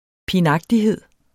Udtale [ pinˈɑgdiˌheðˀ ]